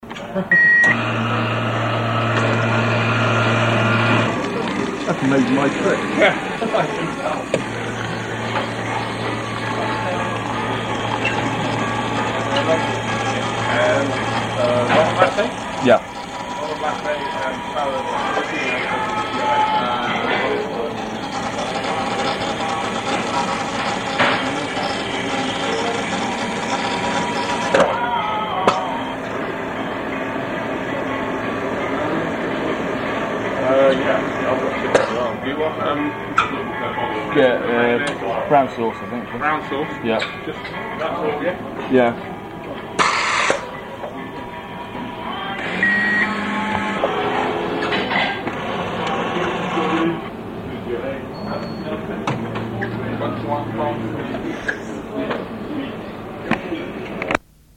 Coffee machine on a DFDS cross-channel ferry.
Good job I had my cassette recorder handy.